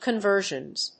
/kʌˈnvɝʒʌnz(米国英語), kʌˈnvɜ:ʒʌnz(英国英語)/